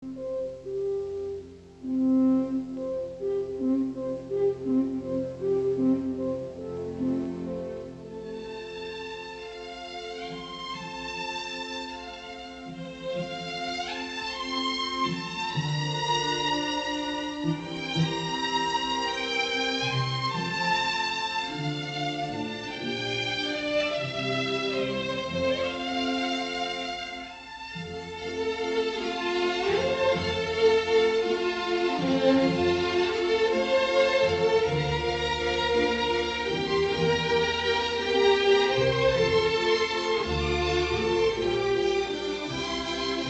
Arranging Symphonic, Piano, and Vocal Works for Performance on Carillon
I find that as a general rule, I have to slow tempi down from the originals, in order to let the bells fully ring.
mm 1-6: Phrasing and articulations make it lyrical. Staccati in winds means get off baton very quickly, touch it lightly. mm 7-8, staccati stop as horn comes in, bells get lower.
mm 17-23 Violin rapid 16th notes give light harmonies.